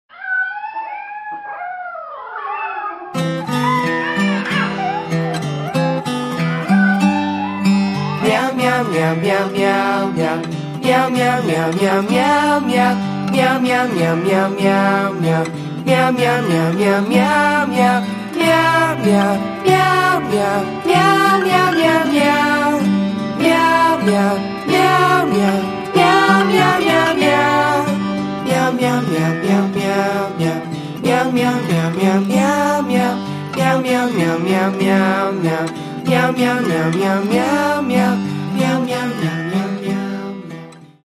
• Качество: 320, Stereo
мяу
meow
Очень слезливое мяуканье